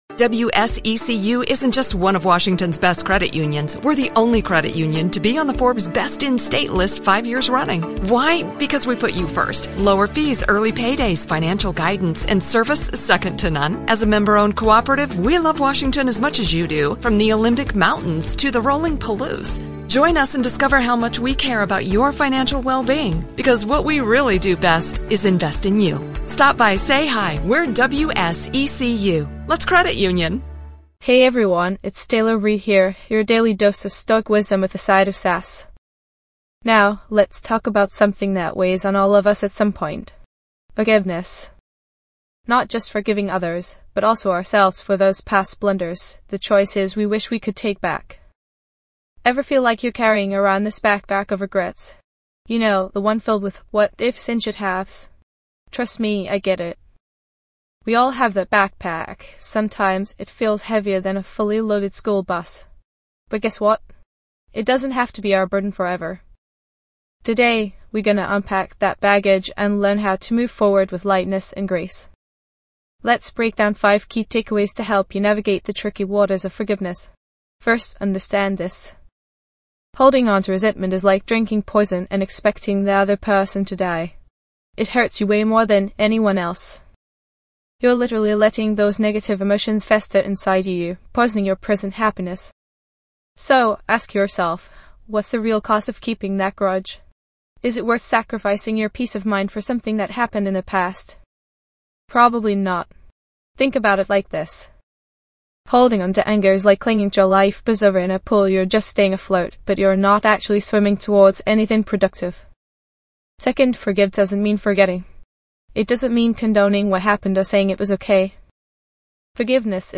Self-help, Mental Health, Personal Development, Inspirational Talks
This podcast is created with the help of advanced AI to deliver thoughtful affirmations and positive messages just for you.